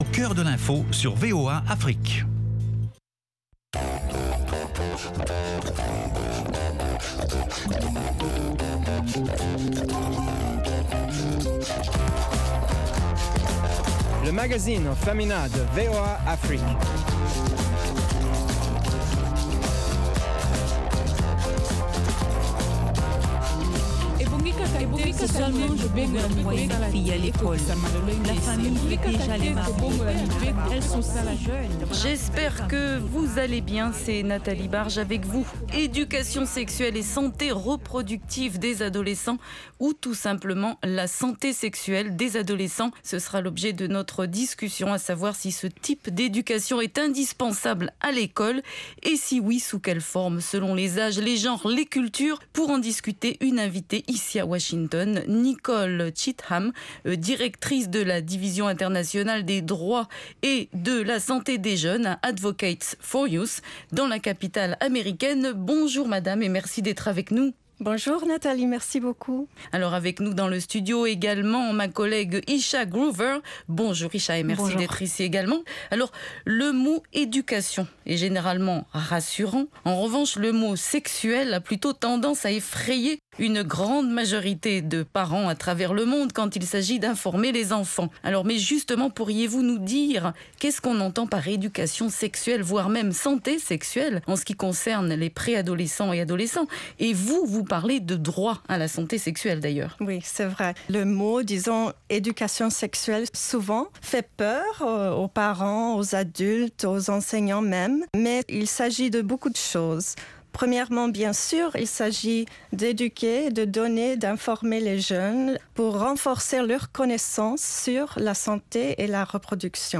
LMF présente également des reportages exclusifs de nos correspondants sur le continent.